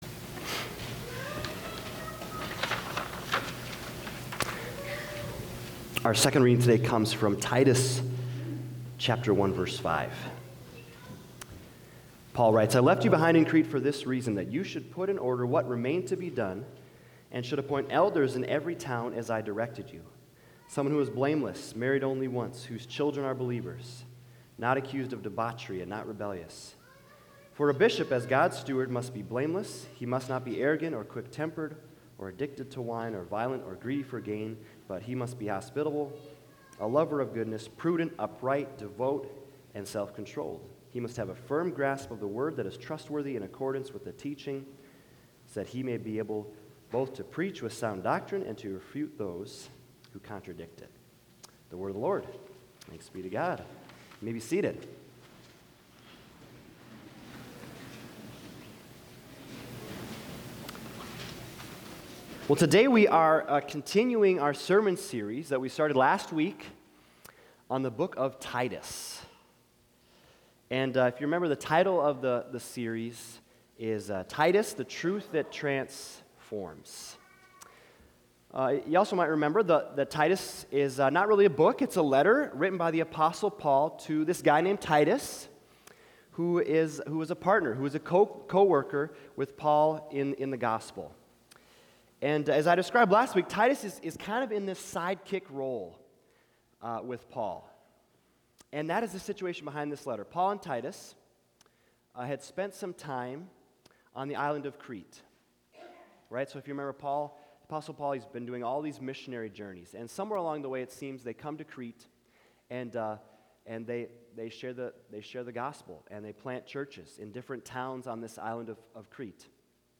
Sermons 2023 - Bethesda Lutheran Church